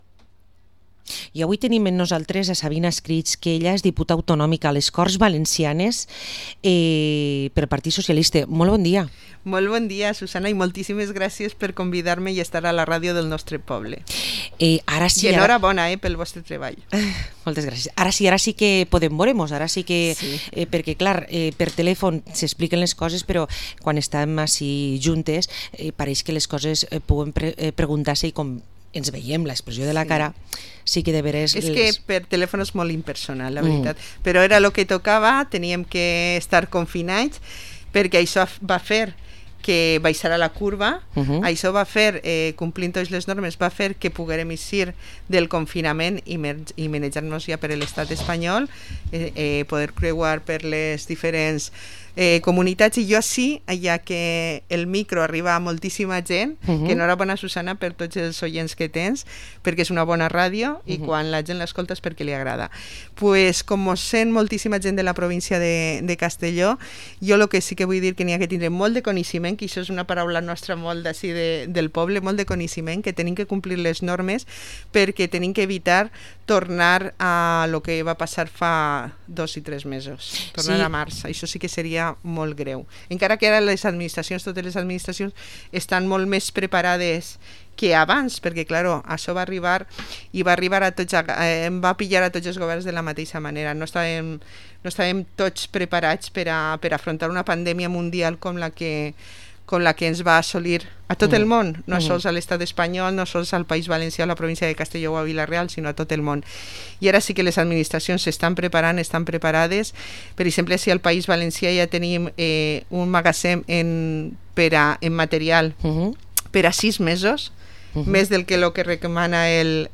Entrevista a la diputada autonómica del PSPV-PSOE, Sabina Escrig